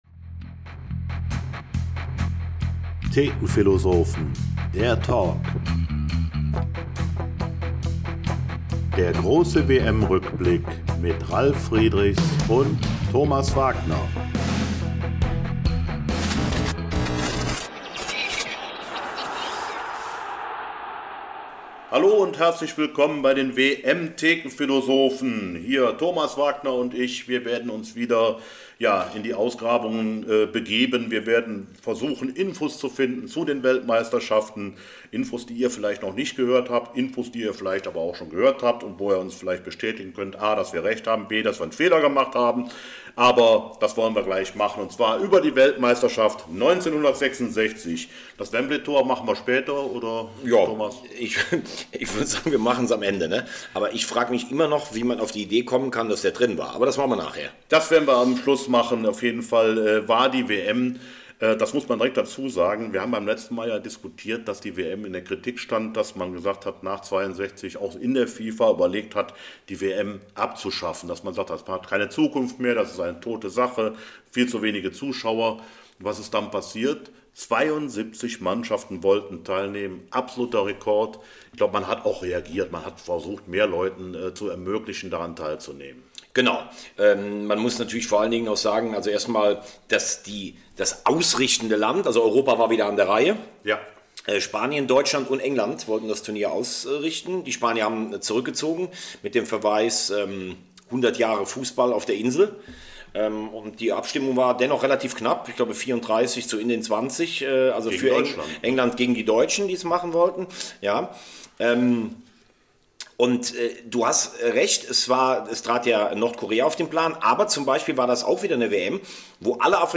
Thekenphilosophen - Der große WM-Rückblick (5) - WM 1966 - ... und ewig grüßt das Wembley-Tor - Folge 11 ~ FC-Thekenphilosophen - Der Talk Podcast